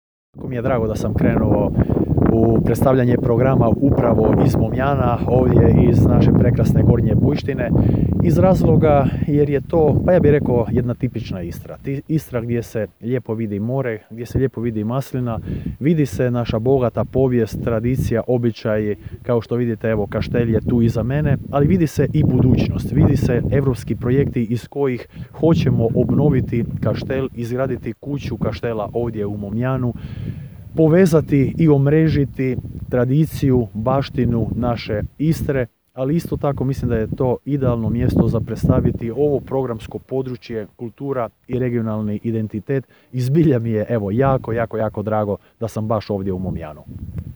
Izjava - Valter Flego u Momjanu
Izjava - Valter Flego u Momjanu.m4a